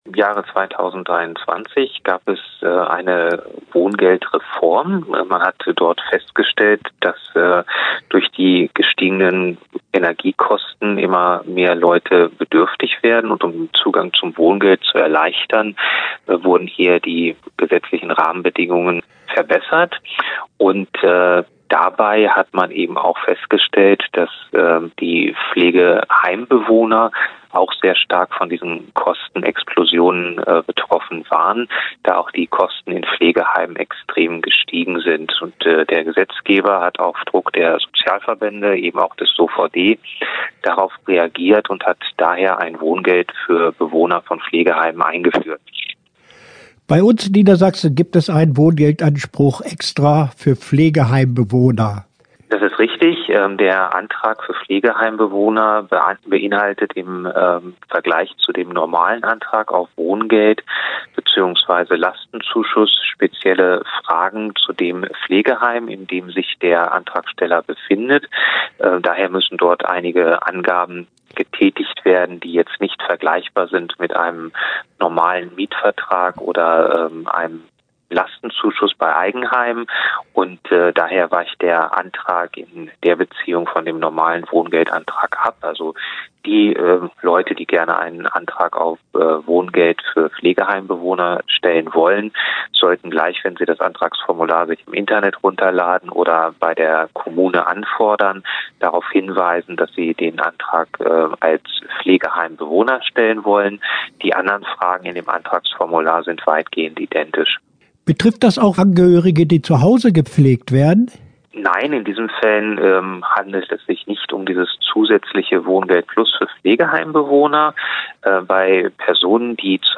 Interview-Wohngeld-plus.mp3